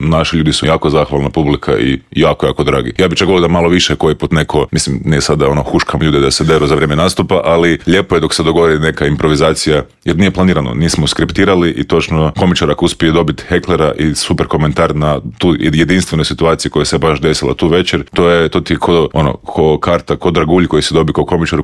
ZAGREB - Novu sezonu intervjua na Media servisu otvorili smo laganom temom.